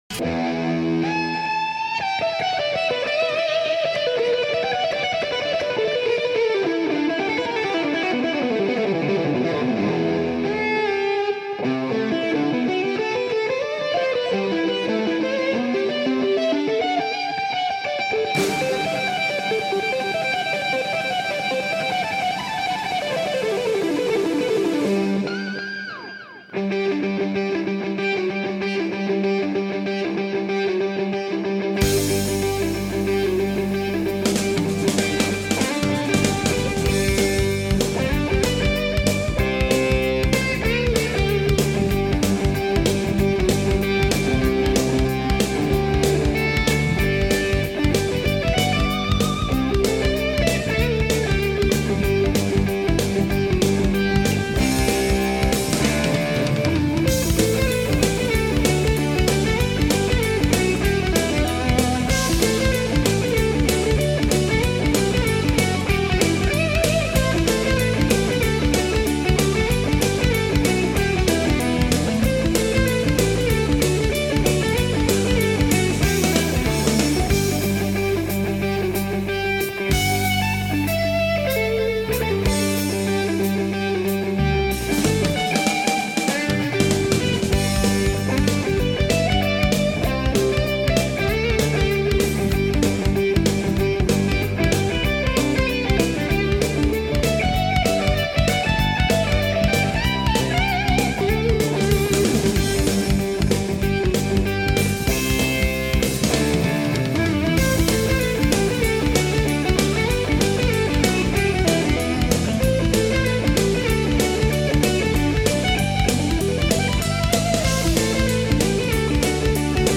BPM93-189
Audio QualityPerfect (Low Quality)